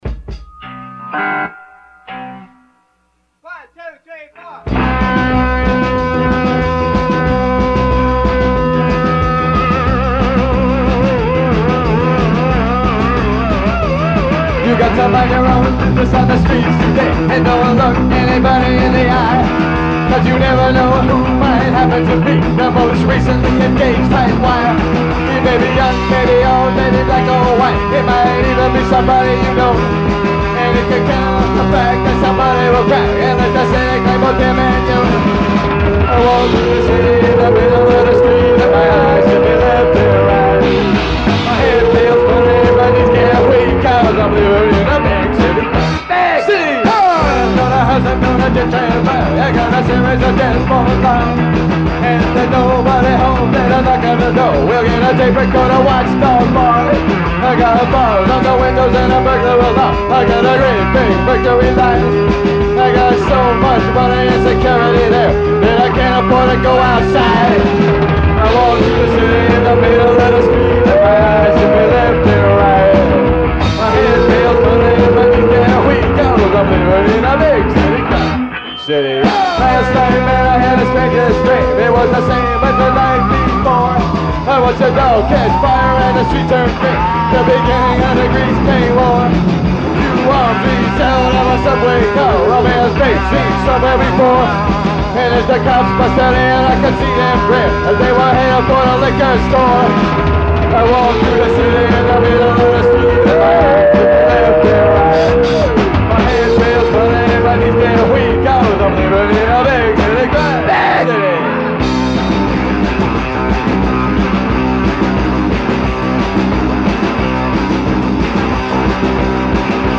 Rehearsals 5-12 + 17-1983